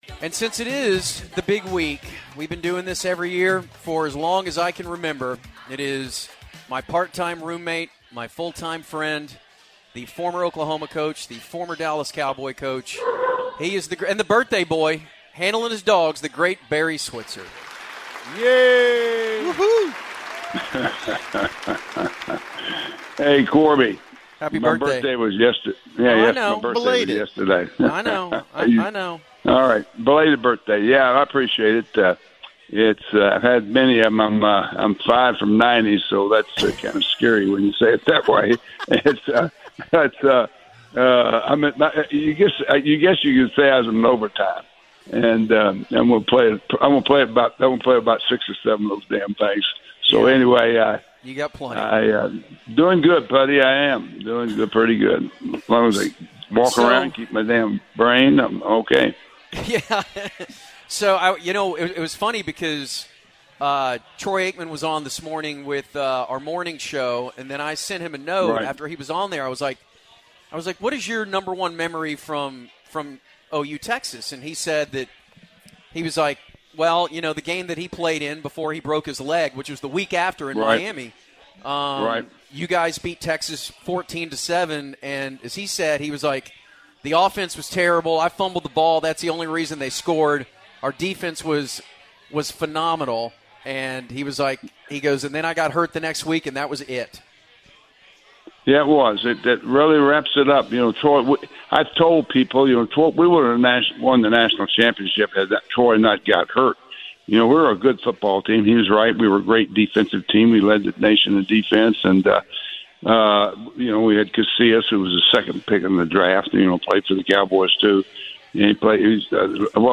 Hardline had their yearly visit with Coach Switzer on TX/OU week and they take a call from the Fake Jerry Jones.